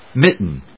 音節mit・ten 発音記号・読み方
/mítn(米国英語)/